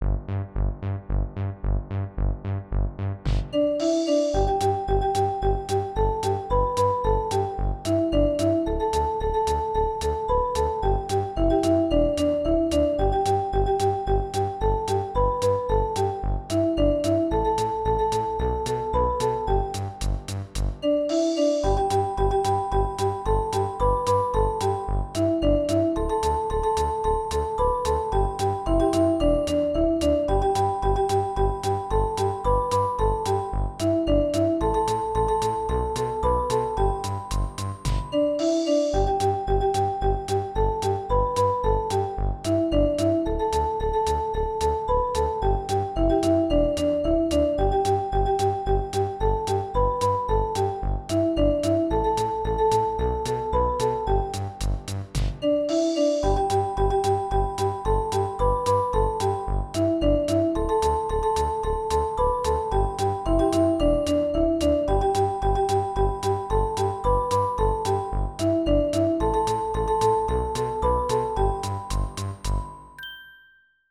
マリンバがコミカルなメロディーをリズムに乗せて奏でます。